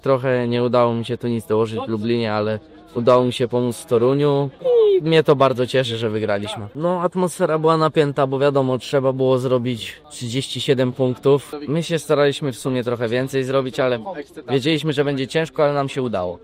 Wypowiedzi po finale: